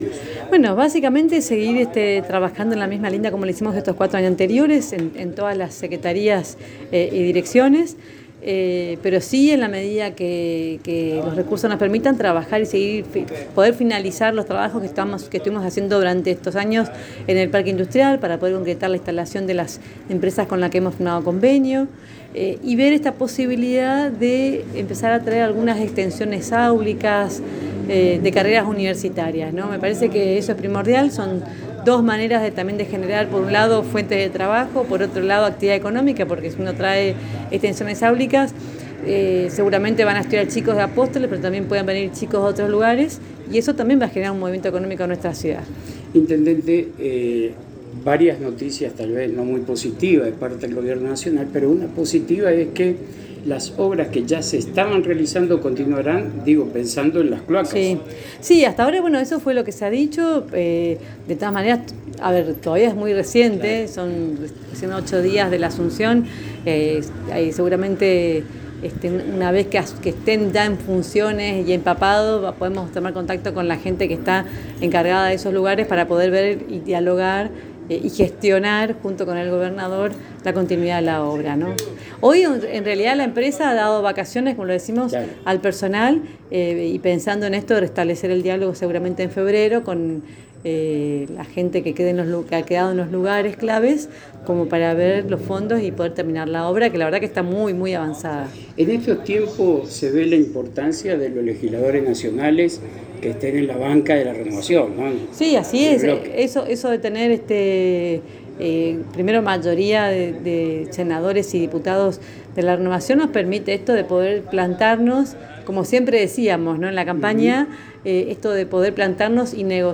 En diálogo exclusivo con la ANG contó cuales serán los pilares de su gestión en los próximos cuatro años sin dejar de atender ningún sector. Se pondrá especial atención a la conclusión de la obra del Parque Industrial, traer ofertas académicas para los jóvenes apostoleños y localidades vecinas y continuar con el plan de desarrollo urbanístico.